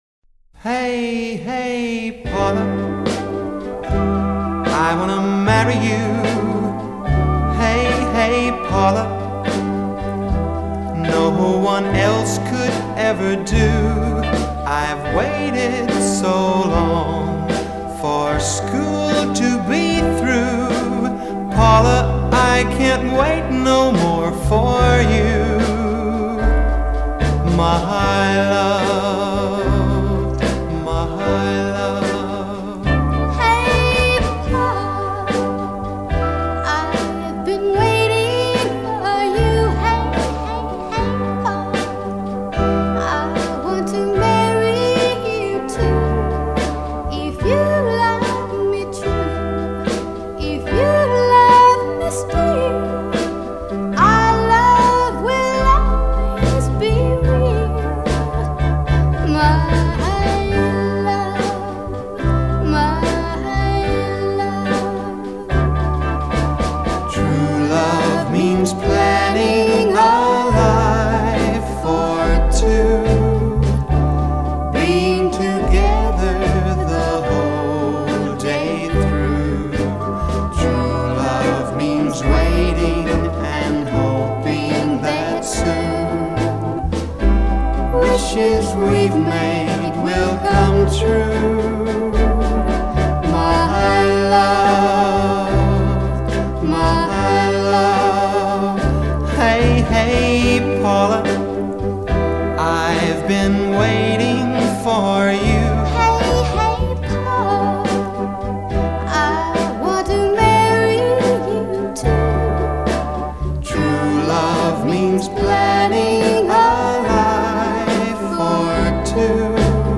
американского дуэта